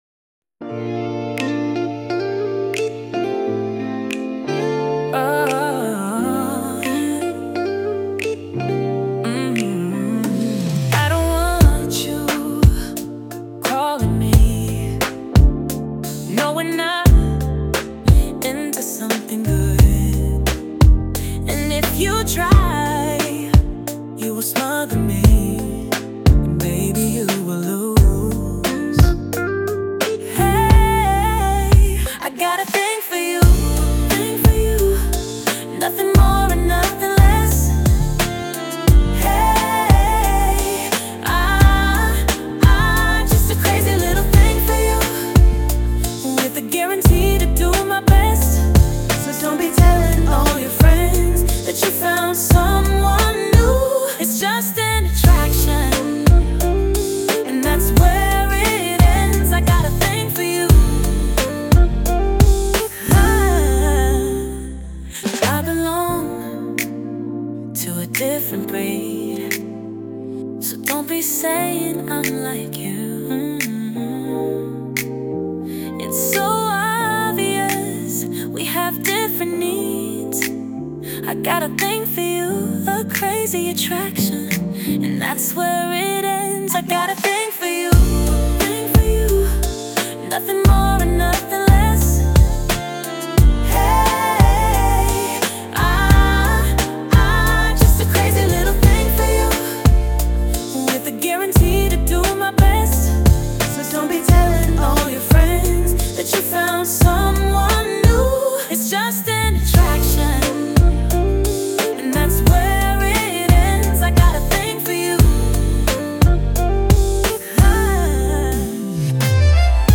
Dive into our latest indie pop-rock releases.
sultry
Pop rock
smooth and seductive